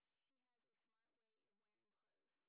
sp26_white_snr30.wav